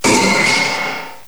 cry_not_mega_pinsir.aif